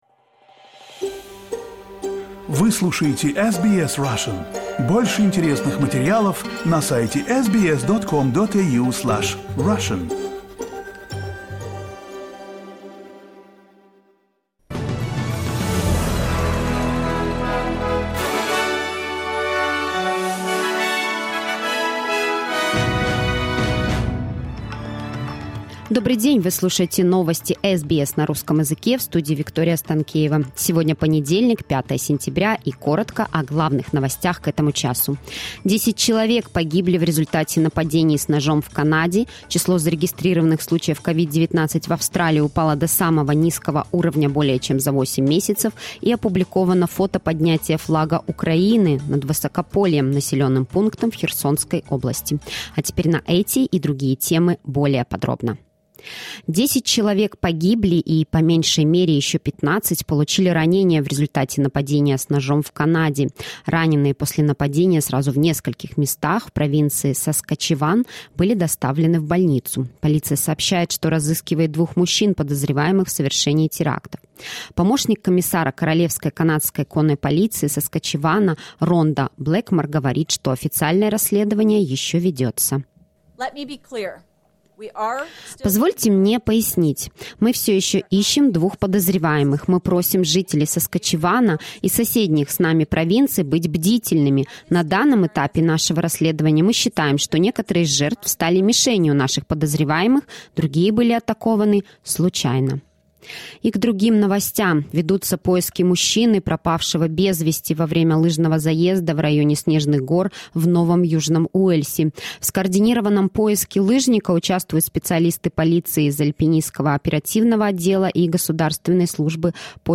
SBS News in Russian - 05.09.2022